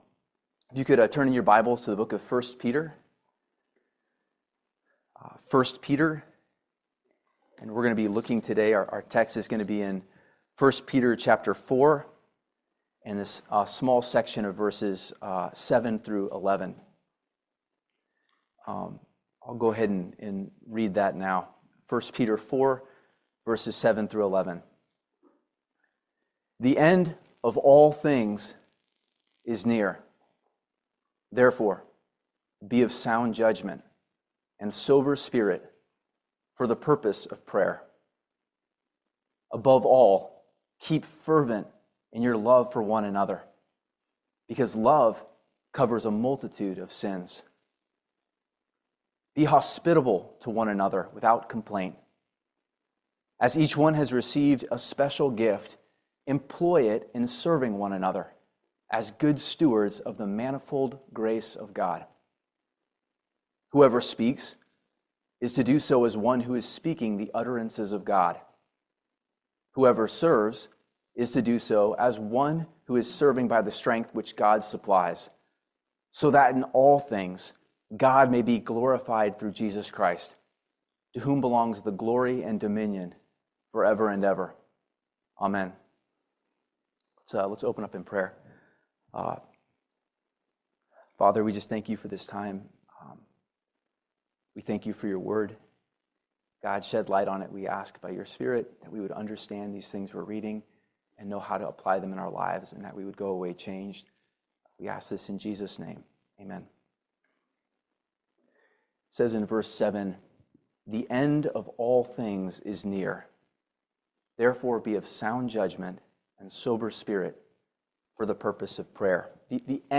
1 Peter 4:7-11 Service Type: Family Bible Hour Believers glorify God in Christ through hospitality and use of spiritual gifts.